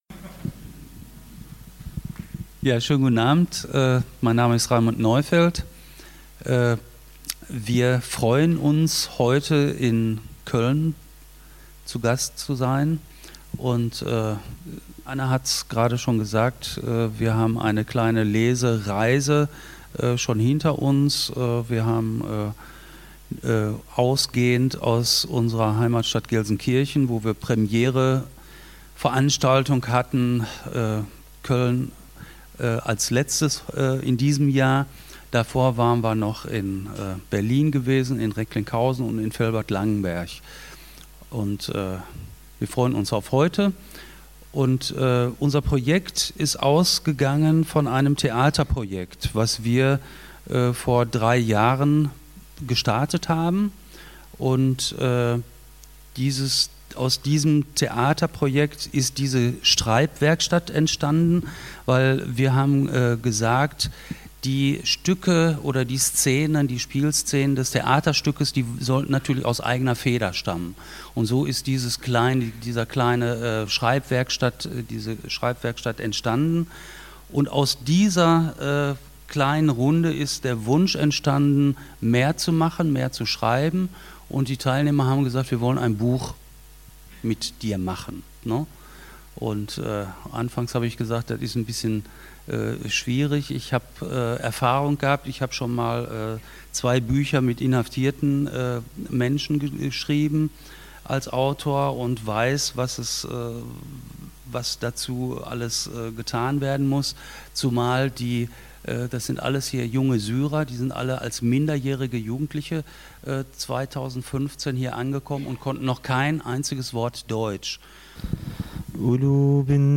Die Lesung fand am 16. Dezember in den Ehrenfeldstudios in Köln statt.